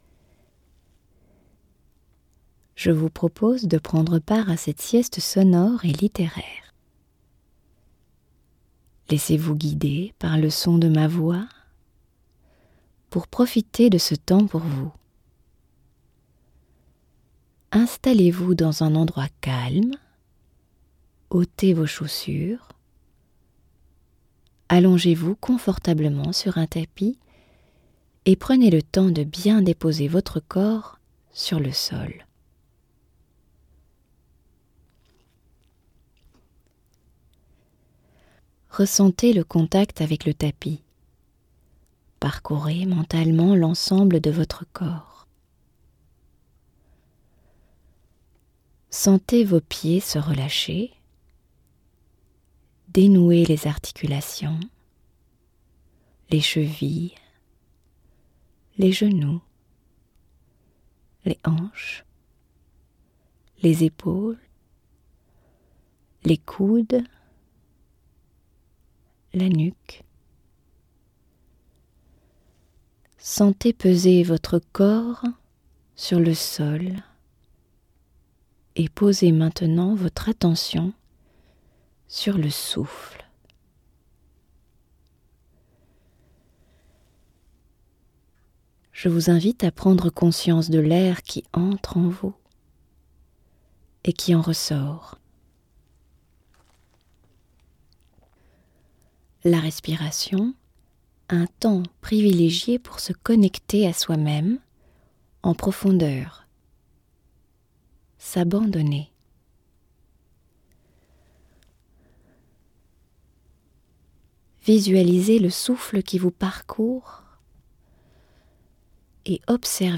Lectures : Gérard de Nerval, El Desdichado tiré de Les Chimères , 1854 Arthur Rimbaud, Voyelles tiré de Poésies , 1891 Victor Hugo, Le Satyre tiré de La Légende des siècles , 1859